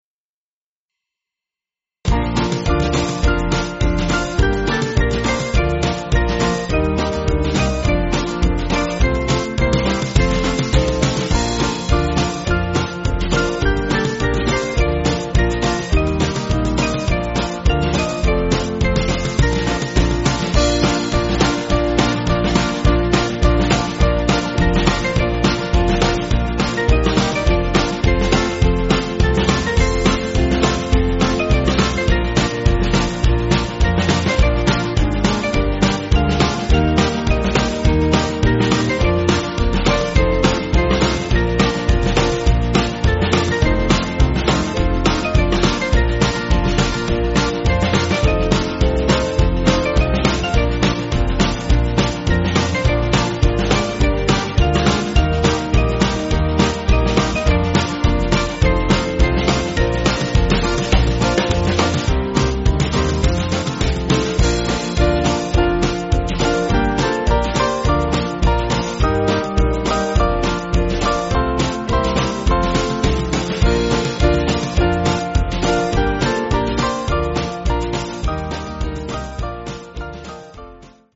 Small Band
(CM)   3/Bb